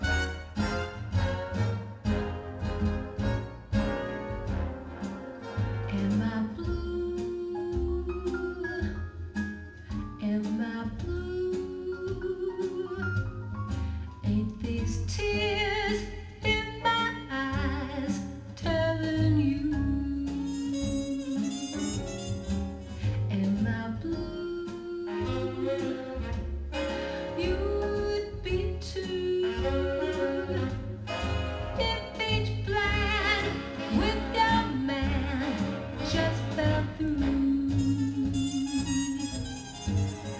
昨年12月の無償ルームチューニングで下調べのための調音を行い、平成27年1月に調音パネルを納品してルームチューニングが完成したオーディオ専用ルームです。